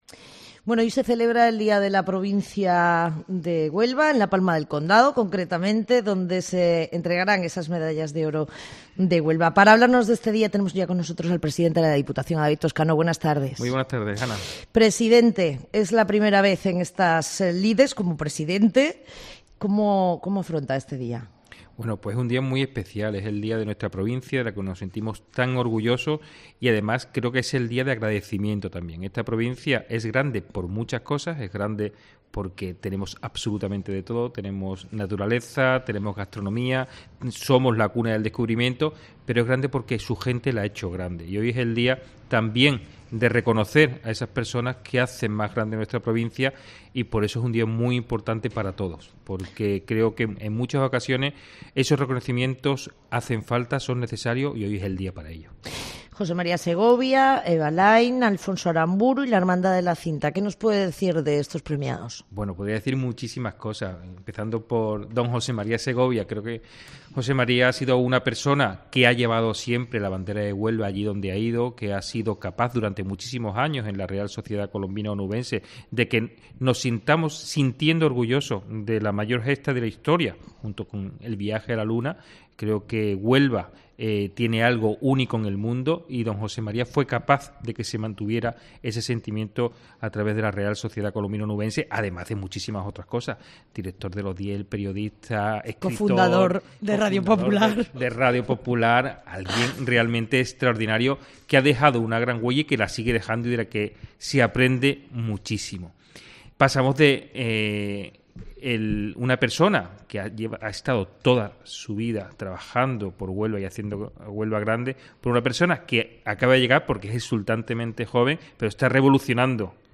Entrevista al presidente de la Diputación de Huelva, David Toscano